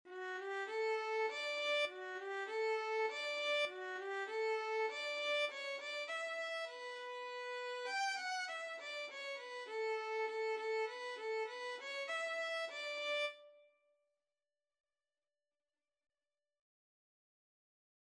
Moderato
3/4 (View more 3/4 Music)
D major (Sounding Pitch) (View more D major Music for Violin )
Violin  (View more Beginners Violin Music)
Traditional (View more Traditional Violin Music)
dona_blancaVLN.mp3